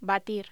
Locución: Batir